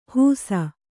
♪ hūsa